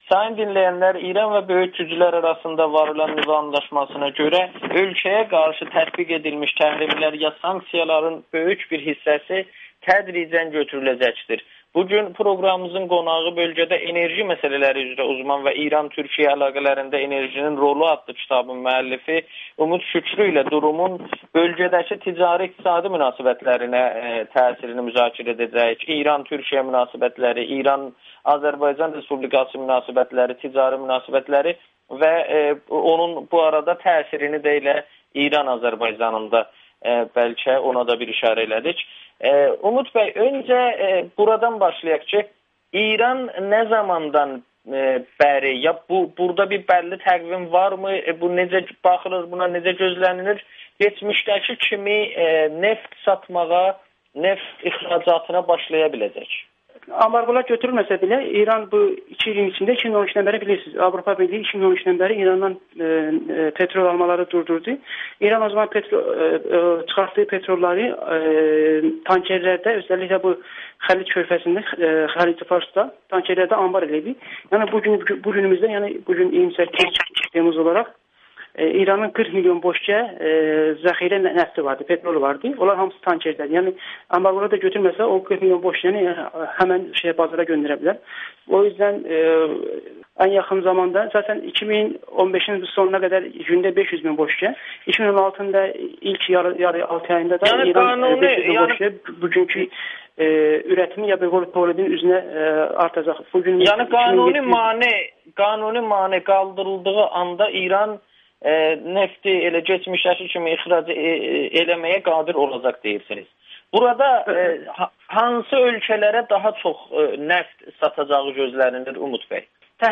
İran, Türkiyə və Azərbaycan arasında enerji ticarətinin gələcəyi [Audio-Müsahibə]